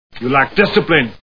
Kindergarten Cop Movie Sound Bites